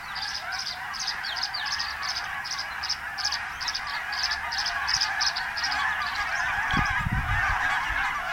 Вы услышите их щебет, трели и другие голосовые реакции, которые помогут вам ближе познакомиться с этими удивительными птицами.
Звук ласточек на дереве, их щебет и стая гусей внизу